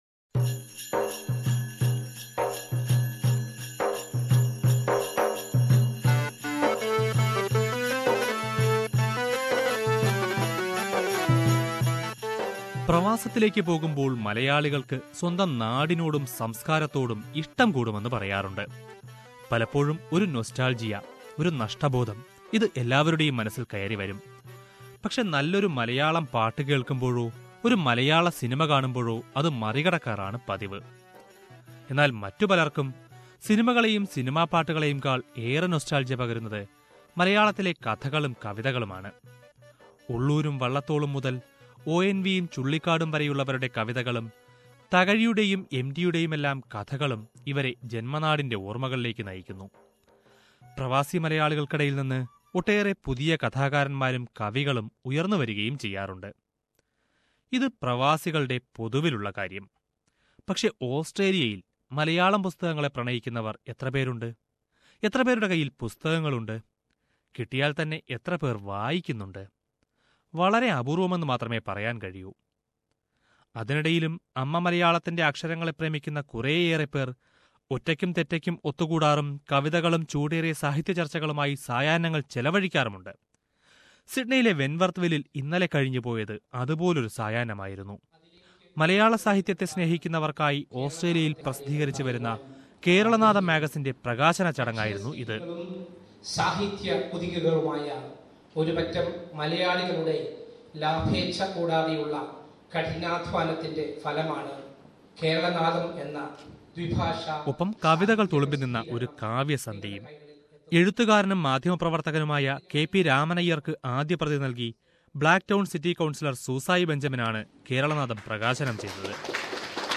The 'Poetic Evening' or 'Kavya Sandhya', organised by the Malayalee community in Sydney, and the release of the 2013 editing of Malayalam literary magazine Kerala Nadam, was a different experience. Let us listen to a report prepared by SBS Malayalam from the venue.